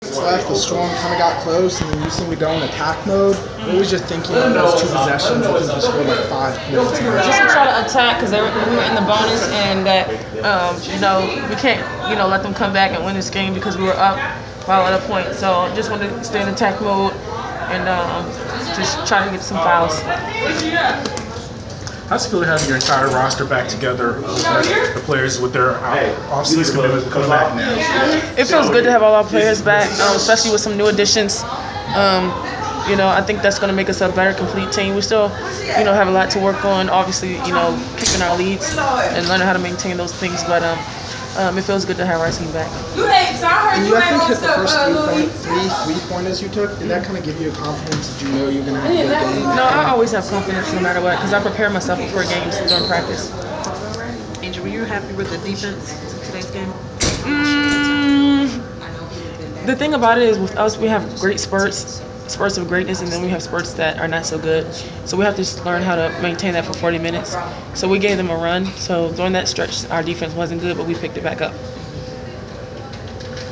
Inside the Inquirer: Postgame presser with Atlanta Dream’s Angel McCoughtry 7.5.15
The Sports Inquirer caught up with Atlanta Dream forward Angel McCoughtry following her team’s 72-64 home win over the Seattle Storm on July 5.